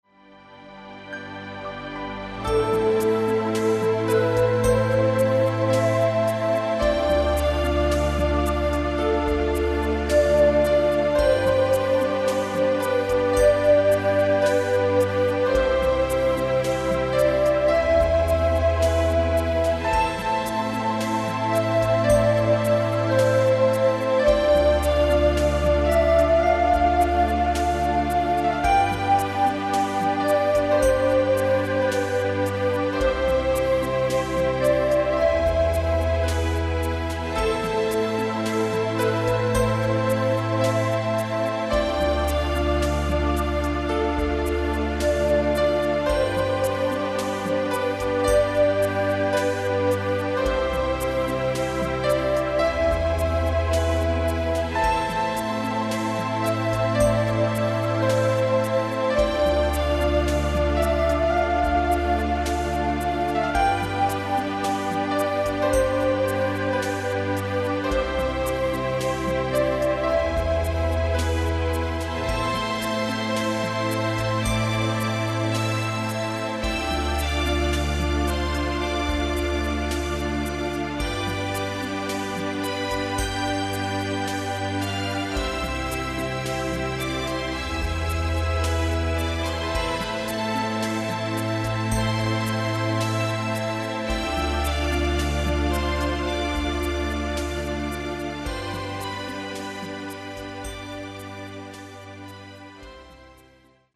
Nadaje to muzyce wi�cej subtelnych energii duszy.